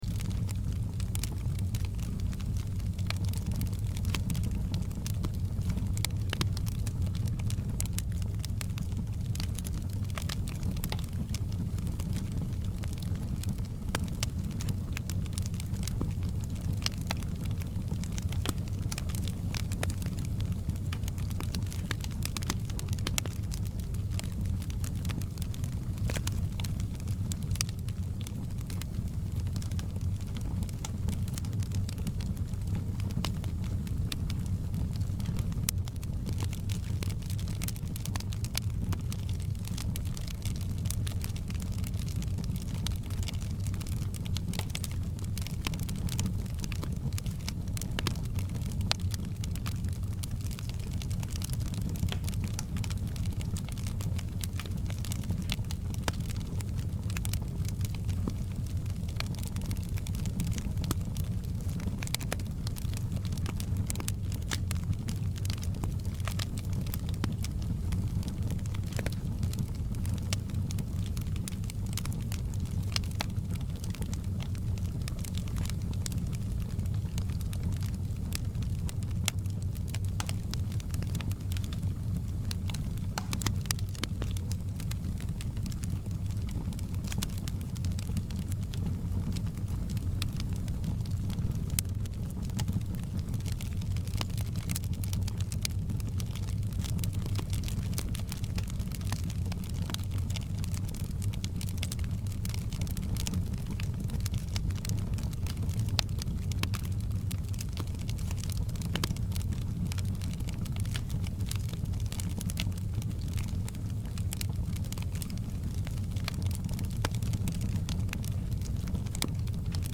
fireplace.mp3